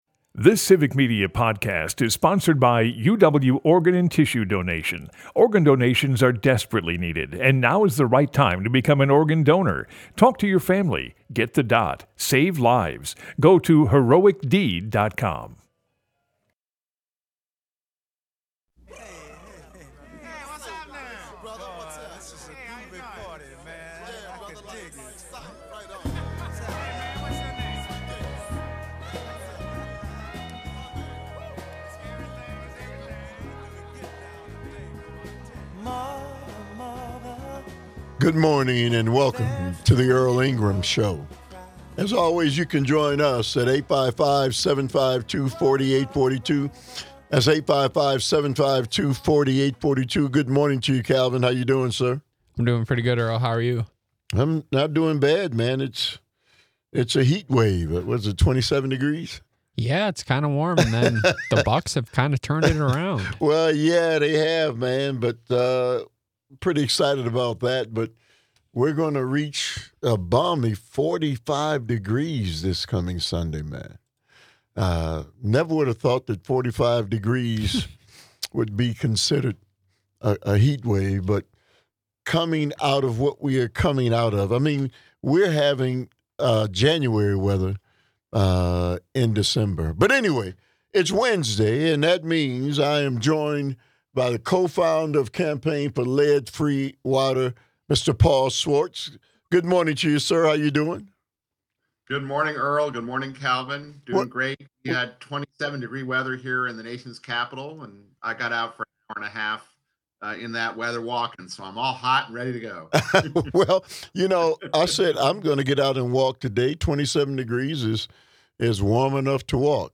In today's conversation on the dangers of lead in water, they focus on the necessity of filtering your water, especially if under a boil advisory as boiling water can increase the concentration on lead.